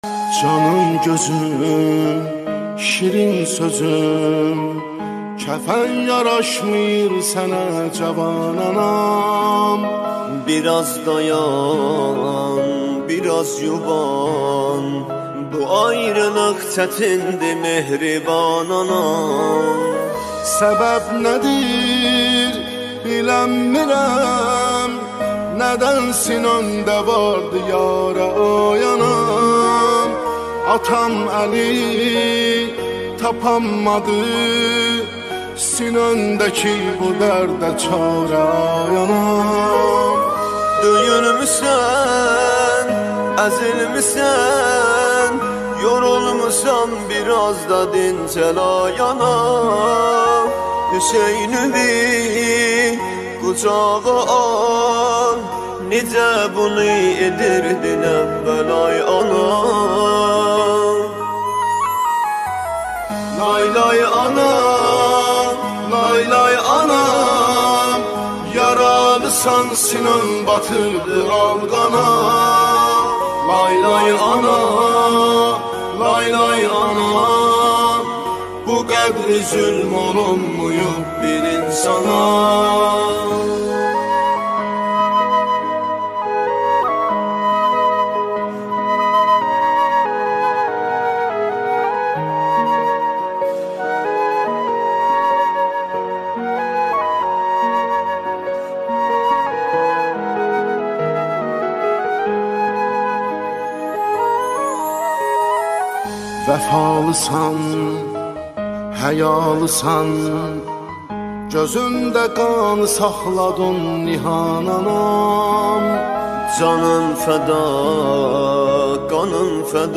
دانلود مداحی ترکی